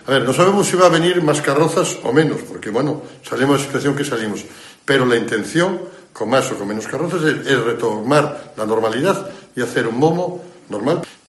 El alcalde de Vilanova de Arousa anuncia la celebración presencial del Momo 2022